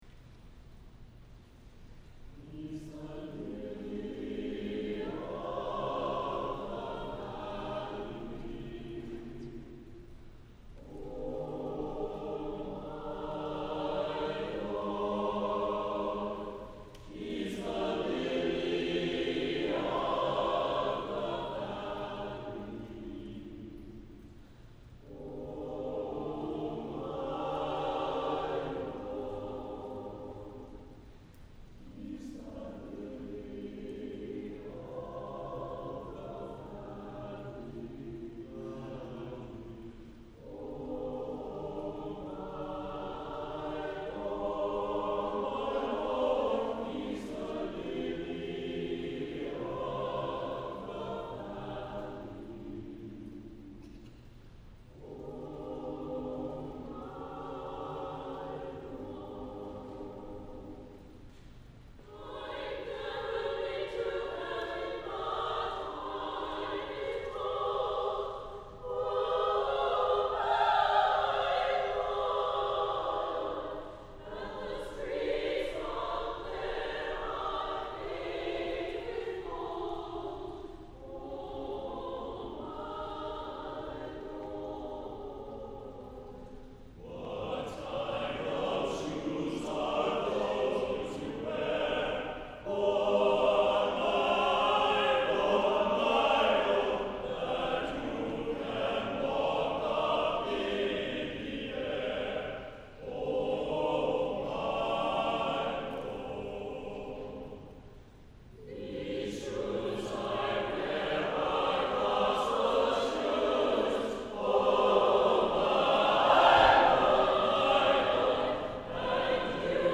Chancel Choir
The Chancel Choir is a capable and dynamic group of enthusiastic singers that rehearses on Thursdays from 7-9pm in the Choir Room, and leads music in worship upstairs in the Sanctuary from September through June.
“He’s the Lily of the Valley” Performed by the United Parish Chancel Choir